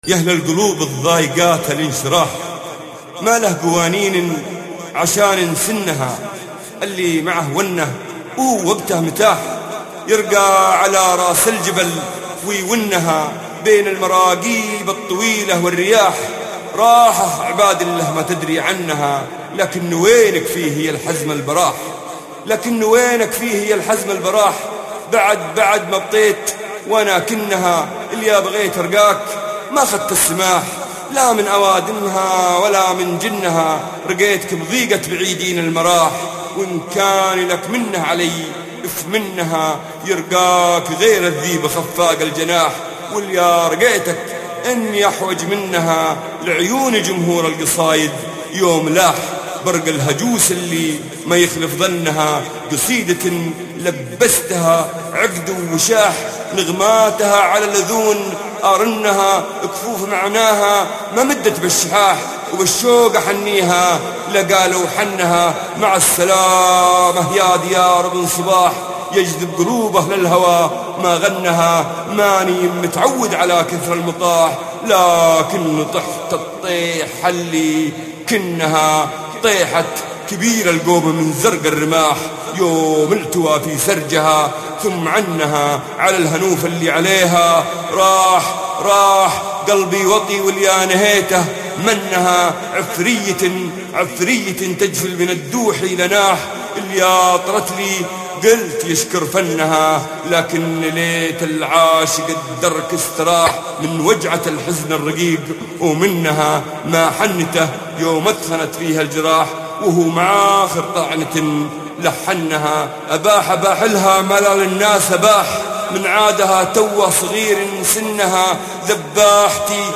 يا أهل القلوب - القاء مساعد الرشيدي